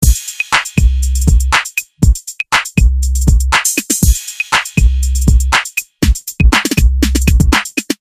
Hip hop beats » Hip Hop Beat Loop5
描述：Hip hop loops
标签： beats hip hop
声道立体声